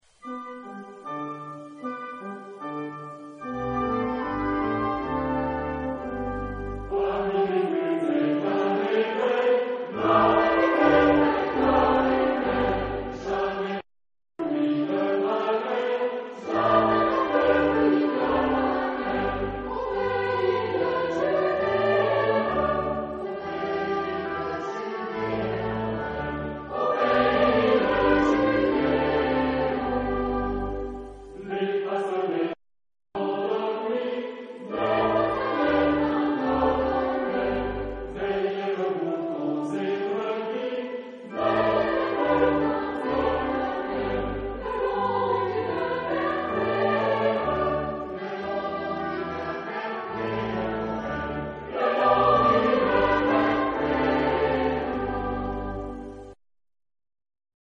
Genre-Stil-Form: weltlich ; Weihnachtslied ; Volkstümlich
Instrumentation: Bläser + Orgel  (5 Instrumentalstimme(n))